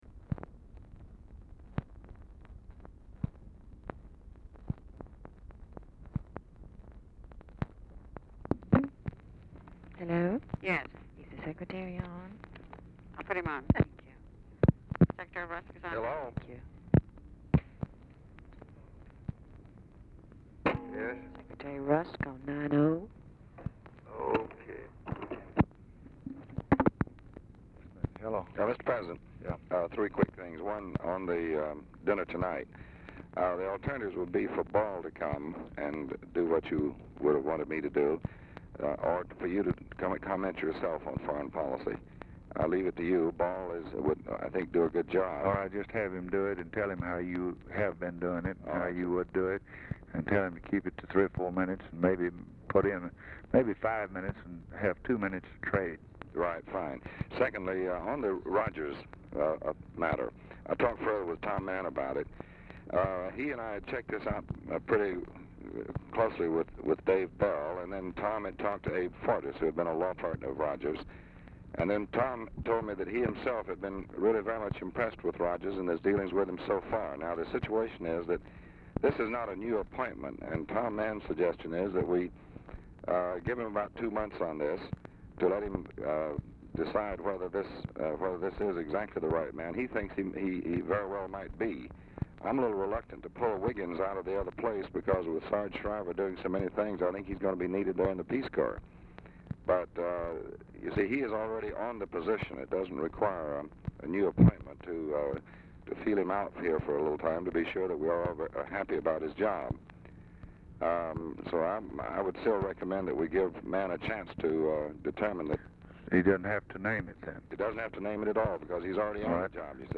Telephone conversation # 1212, sound recording, LBJ and DEAN RUSK, 1/7/1964, 10:15AM | Discover LBJ
Format Dictation belt
Location Of Speaker 1 Oval Office or unknown location
Specific Item Type Telephone conversation